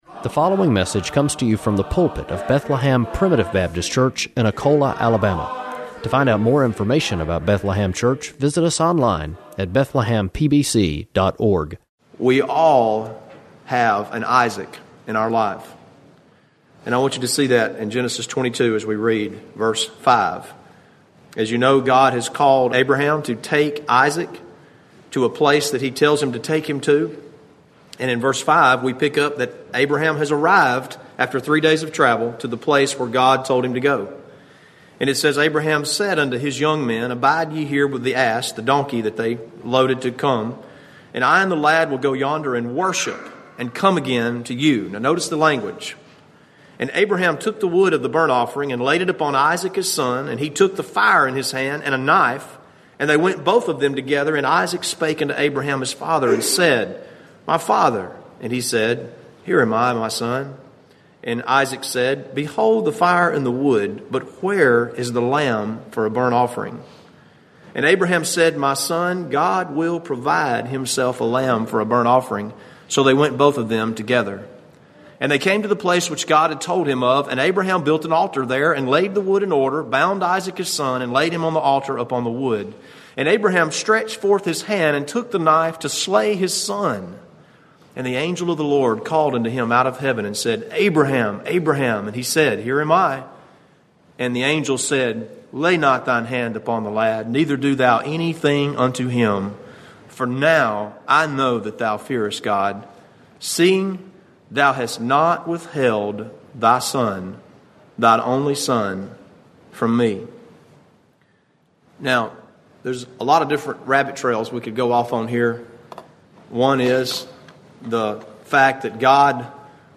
But in reality, we all have an Isaac to lay down on the altar. It might be a spouse, a child, an emotion or some possession – if we hold back anything from the Lord, we fail to make him first. In this message